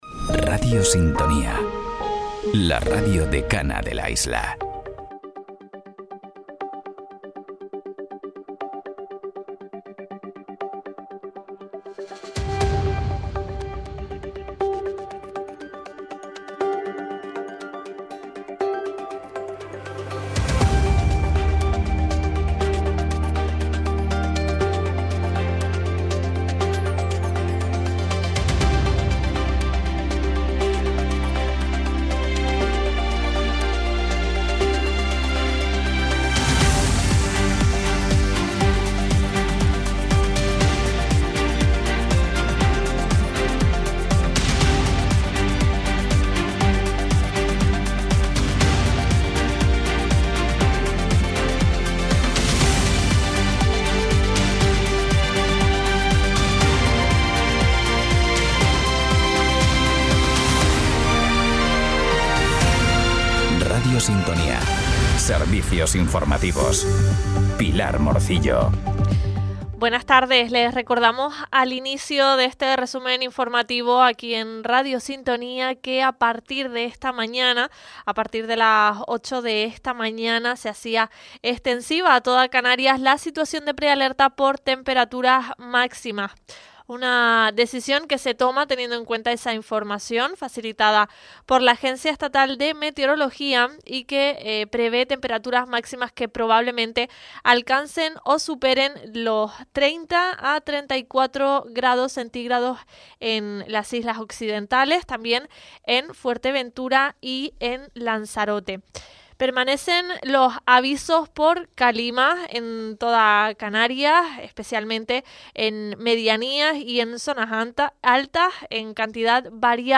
Informativos en Radio Sintonía - 16.09.25